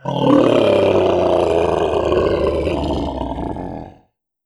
Monster Roars
07. Behemoth Roar.wav